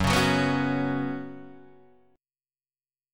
F#sus4 chord